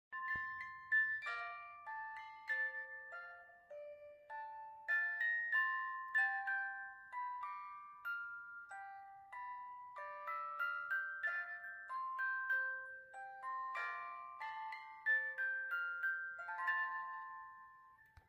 Шкатулка музыкальная с балериной (M302)
Размер шкатулки: 15х10,5х10 см. при открывании крышки играет механическая музыка и вращается балерина.